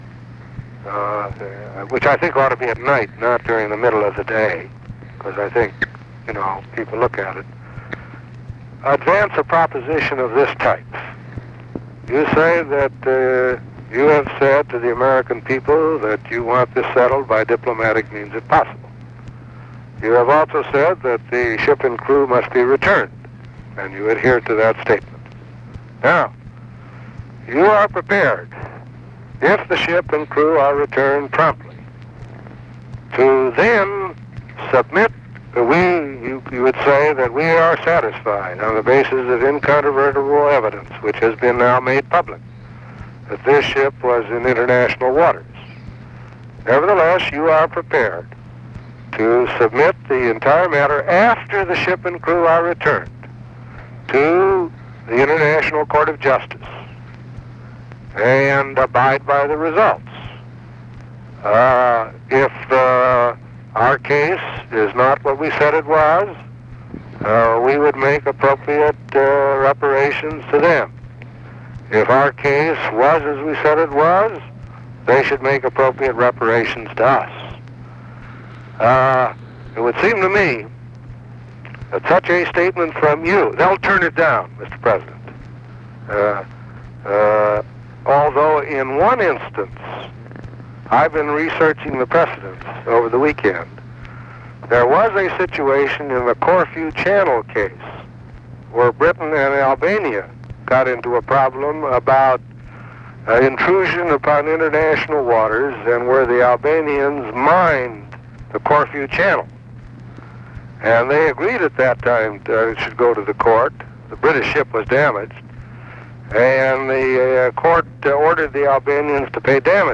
Conversation with ARTHUR GOLDBERG, January 28, 1968
Secret White House Tapes